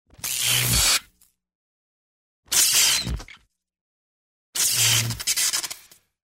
Гудение в гараже ночью